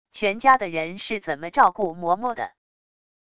全家的人是怎么照顾嬷嬷的wav音频生成系统WAV Audio Player